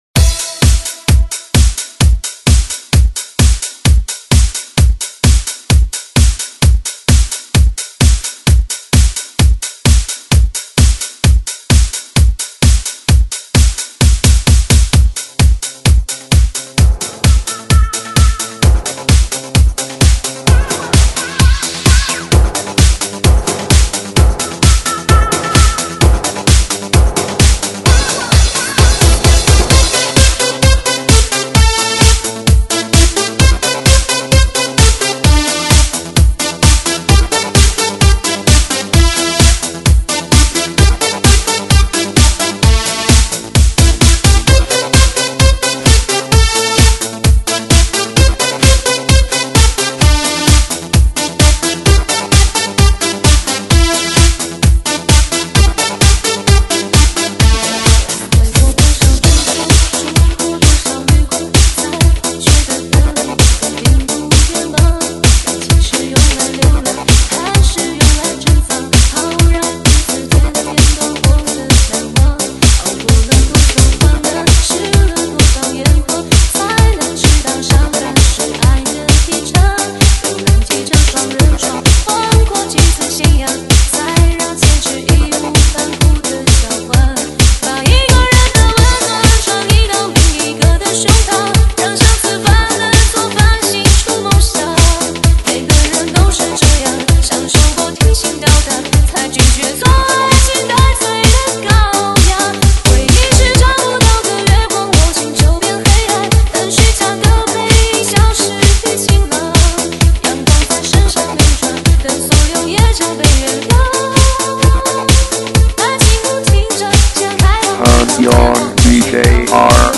飘移新体验 疯狂DJ再现舞曲神话
火热电子舞曲与顶级音乐录音技术的完美结合。
根据汽车空间构造录制，车内也能开PARTY
专业音乐试听室随车带，美国太平洋公司专业混音技术